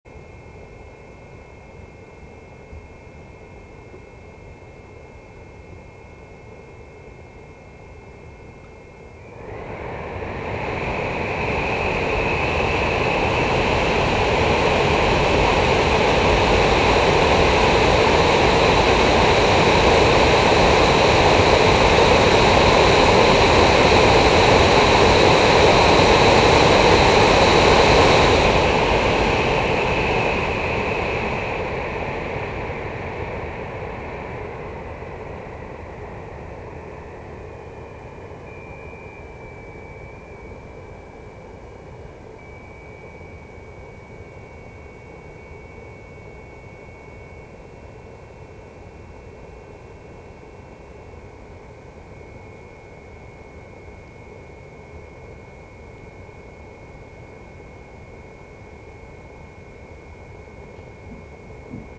From idle speed to max and then back to idle speed again. Microphone about 10 cm away. I noticed that after the first run, the beeping sound came after a few minutes.
This is an Ultra model made in China.